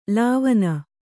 ♪ lāvana